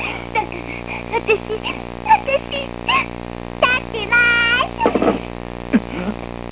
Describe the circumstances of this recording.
Well here are my (low quality) sounds.